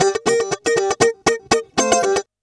120FUNKY04.wav